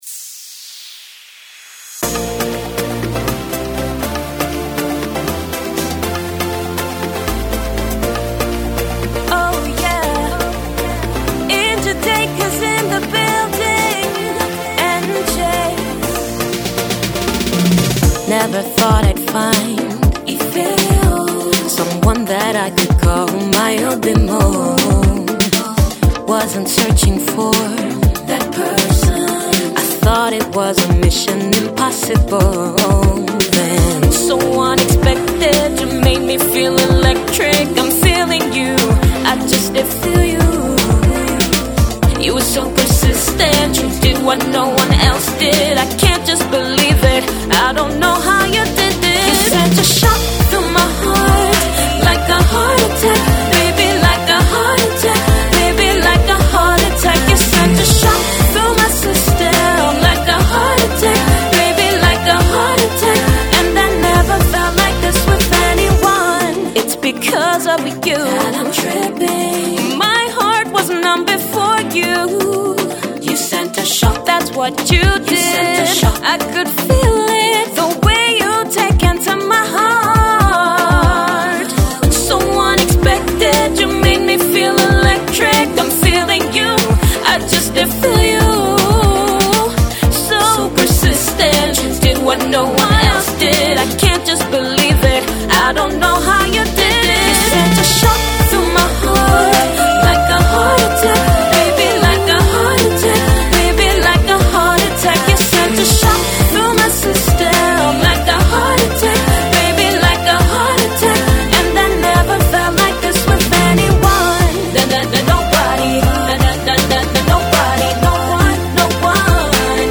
new singer/songwriter
sweet yet powerful voice